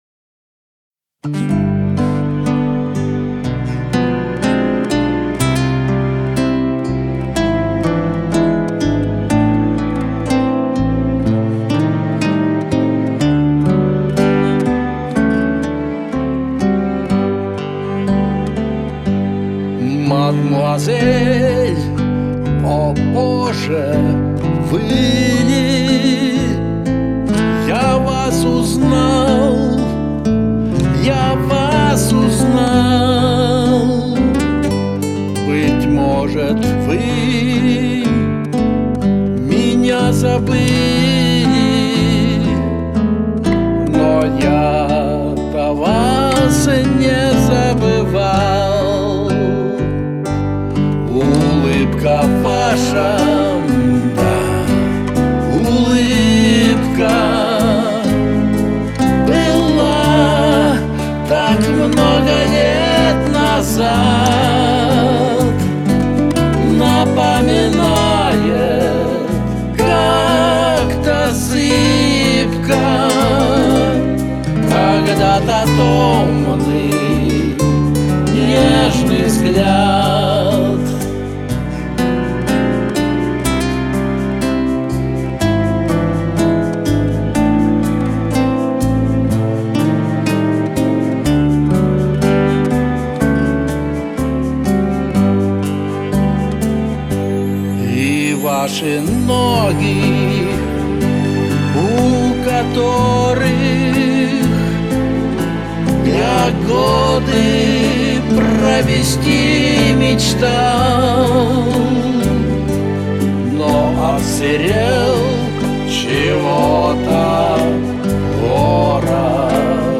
«Романтика романса»
всё распевно, красивые элементы двухголосия.